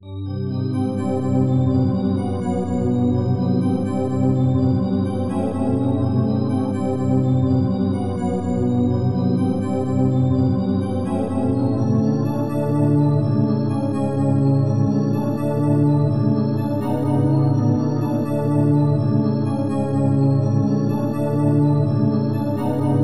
haunting waltz demo (.it, 8ch)